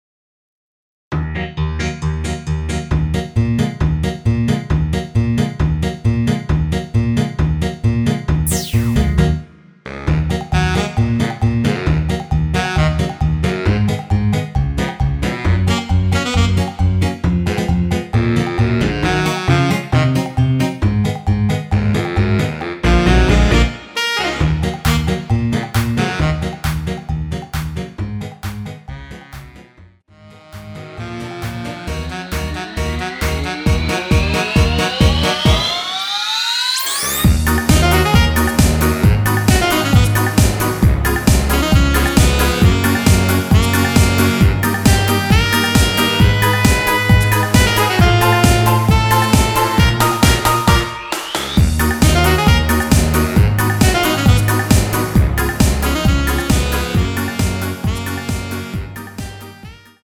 원키에서(-3)내린 MR입니다.
Ebm
앞부분30초, 뒷부분30초씩 편집해서 올려 드리고 있습니다.
중간에 음이 끈어지고 다시 나오는 이유는